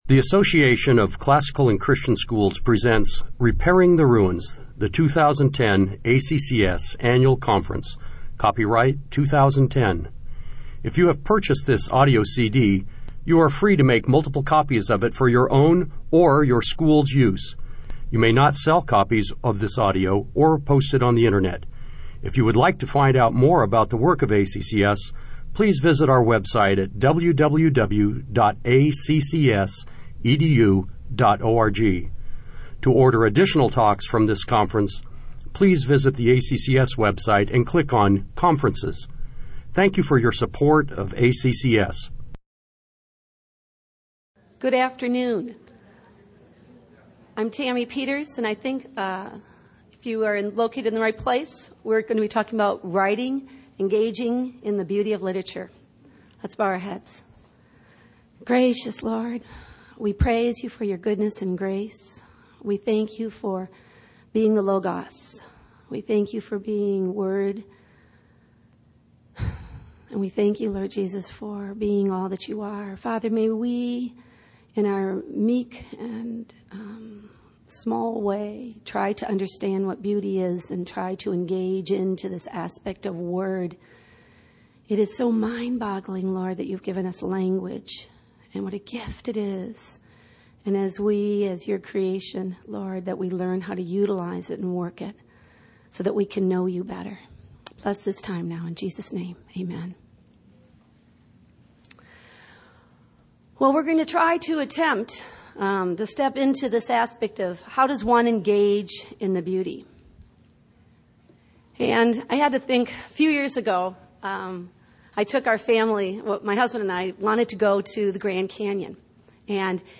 2010 Workshop Talk | 0:47:43 | All Grade Levels, Rhetoric & Composition
The Association of Classical & Christian Schools presents Repairing the Ruins, the ACCS annual conference, copyright ACCS.